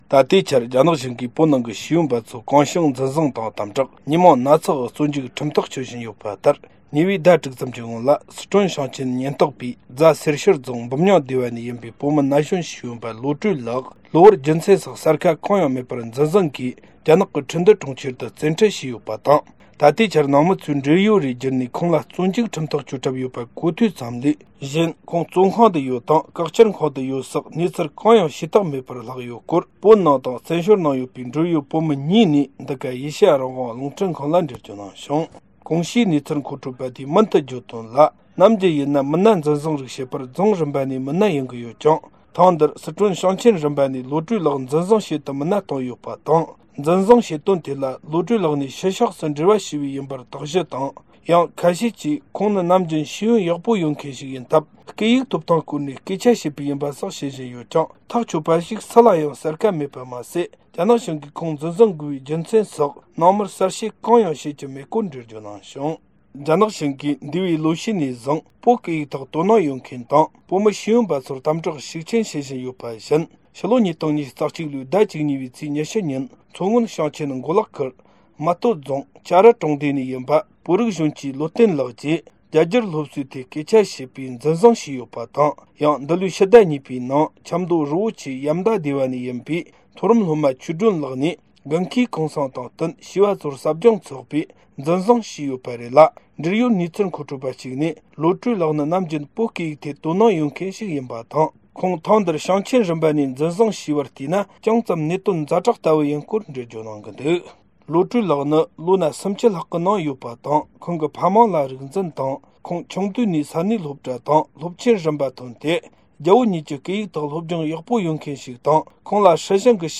བོད་ནང་དང་བཙན་བྱོལ་ནང་ཡོད་པའི་འབྲེལ་ཡོད་བོད་མི་གཉིས་ནས་འགྲེལ་བརྗོད་གནང་བྱུང་།